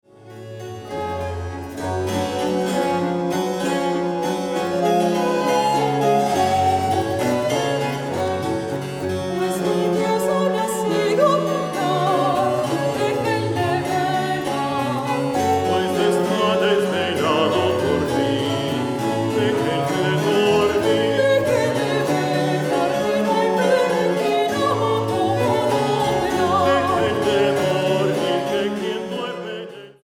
Versiones barroco americano